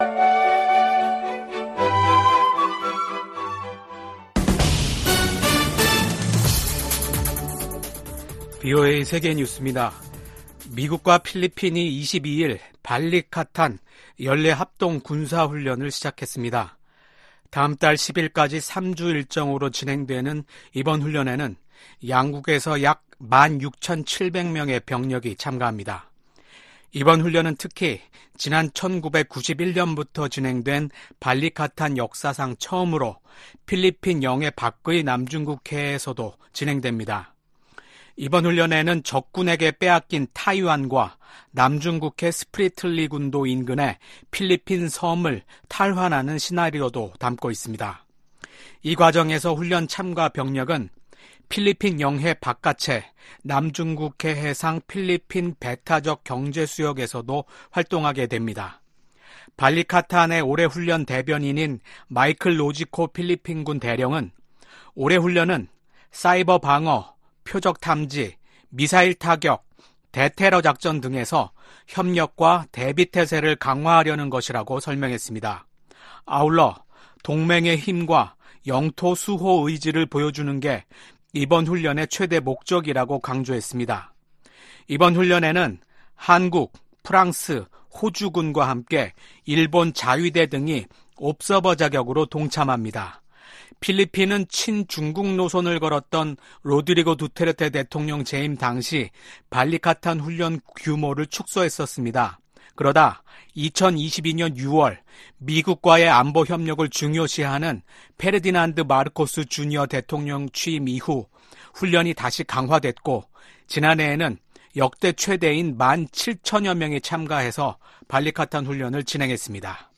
VOA 한국어 아침 뉴스 프로그램 '워싱턴 뉴스 광장' 2024년 4월 23일 방송입니다. 북한이 순항미사일과 지대공 미사일을 시험발사한 지 3일만에 다시 초대형 방사포로 추정되는 단거리 탄도미사일을 발사했습니다. 토니 블링컨 미 국무장관은 북한과 러시아 간 무기 이전 문제를 지적하며 주요 7개국, (G7)이 이를 막기 위한 공동 노력을 강화하고 있다고 밝혔습니다.